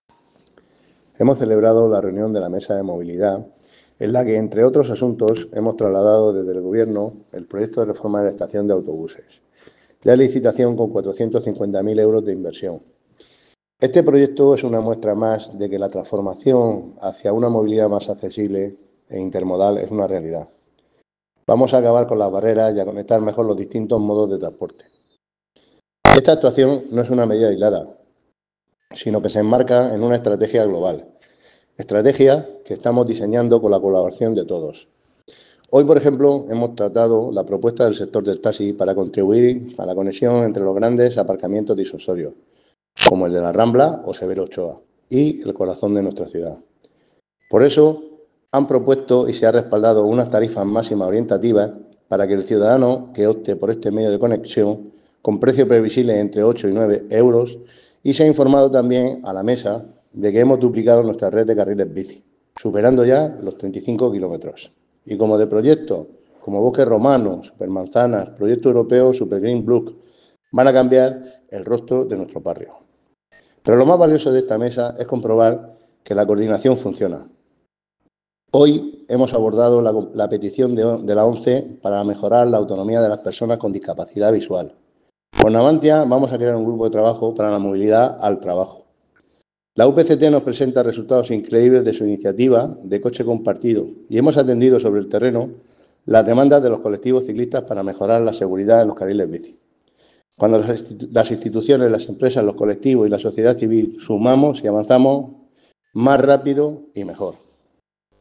Enlace a Declaraciones de José Ramón Llorca.